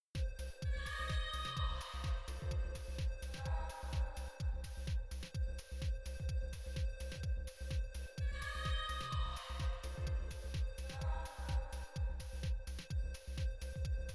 Then I switched polarity on one of them, played them both together and recorded the result.
Conclusion: There is obviously a slight difference because you can still hear a bit of audio in the null test clip with the polarity flipped.